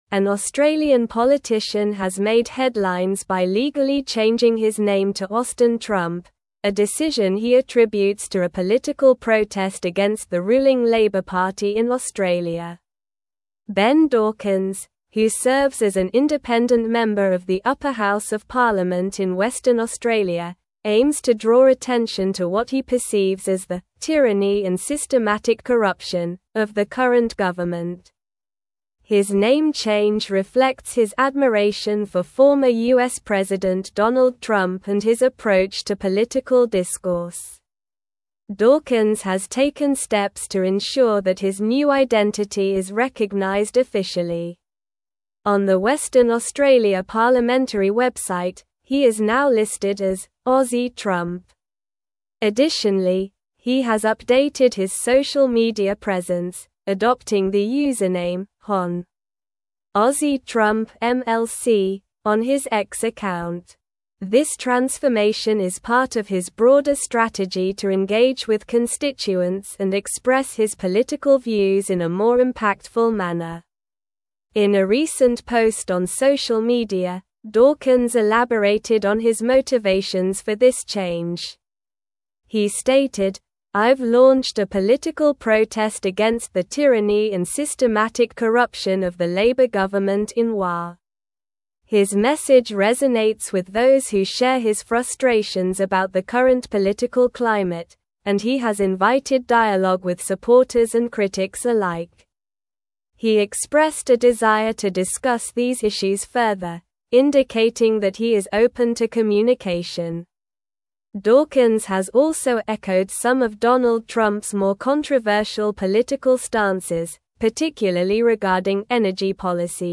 Slow
English-Newsroom-Advanced-SLOW-Reading-Australian-Politician-Changes-Name-to-Austin-Trump.mp3